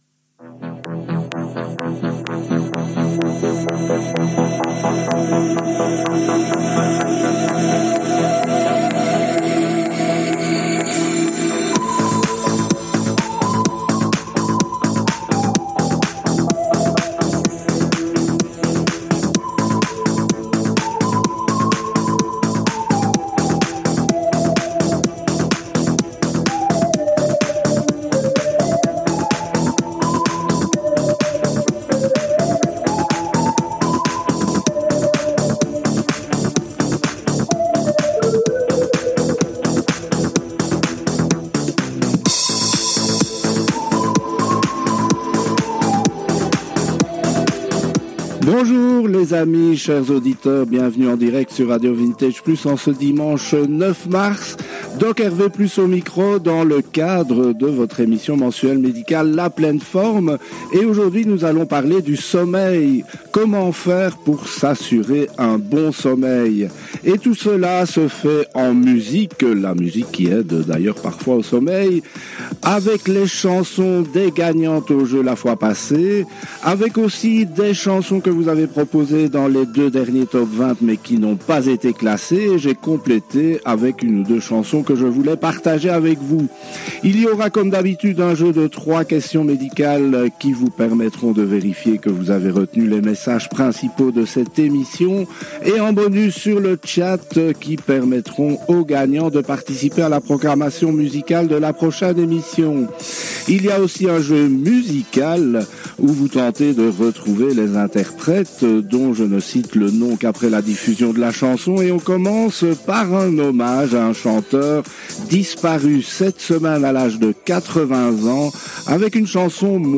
Emission mensuelle médicale et musicale avec des conseils pour mieux dormir